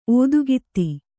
♪ ōdugitti